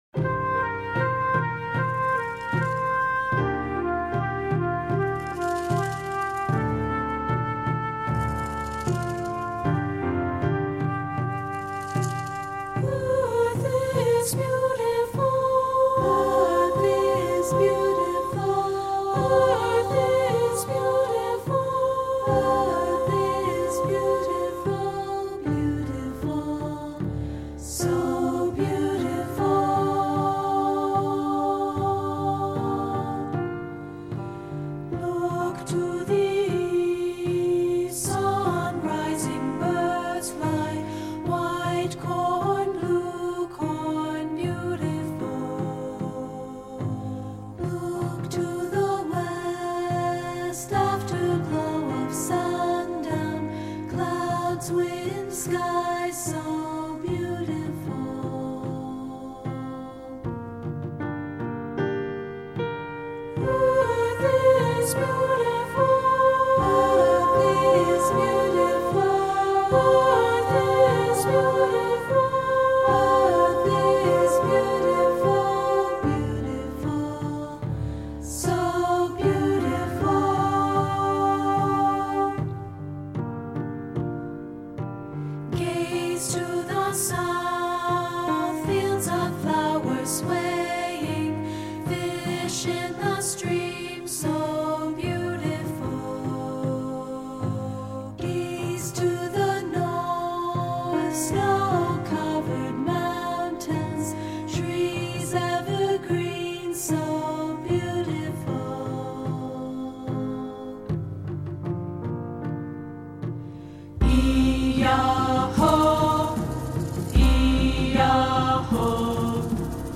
SA and Piano